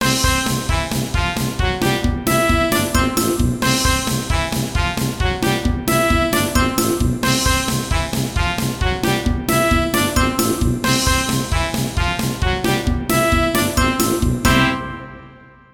Tag Archives: リズミカル
思いがけない誰かが登場したときのミュージック。